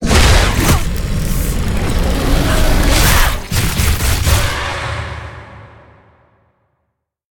Sfx_creature_squidshark_cine_death_01.ogg